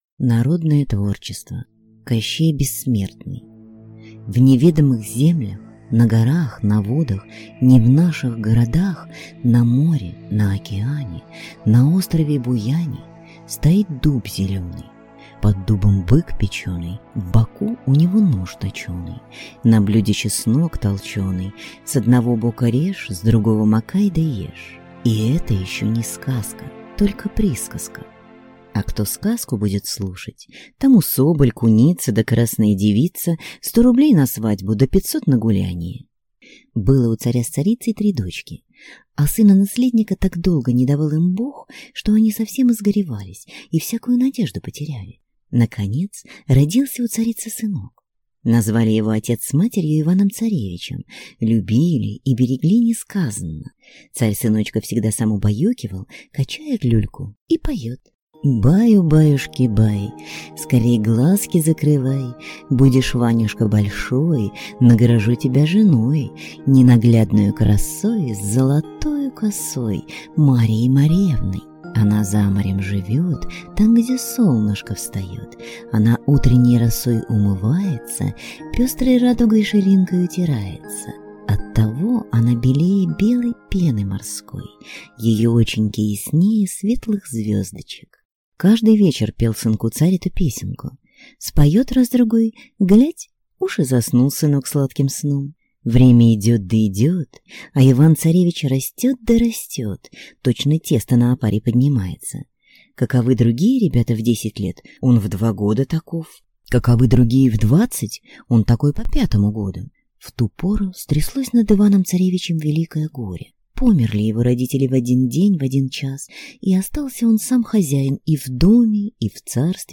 Аудиокнига Кощей бессмертный | Библиотека аудиокниг
Прослушать и бесплатно скачать фрагмент аудиокниги